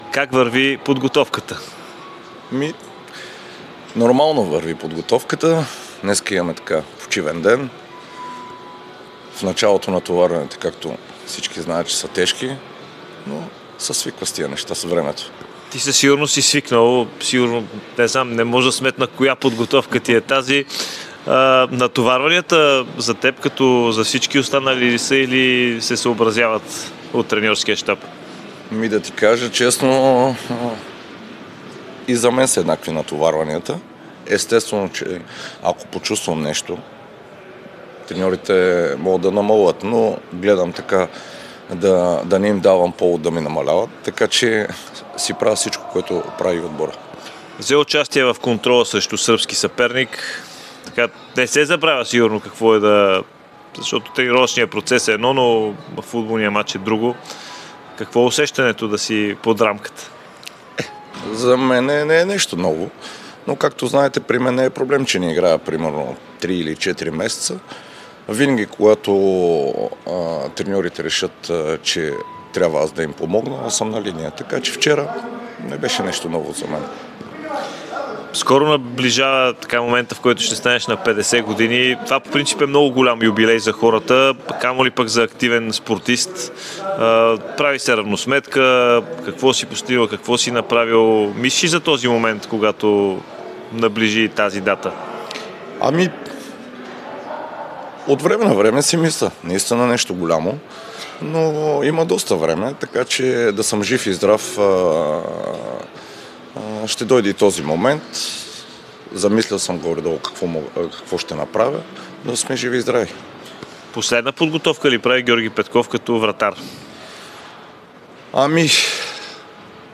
Цялото интервю с Георги Петков очаквайте в сряда сутринта!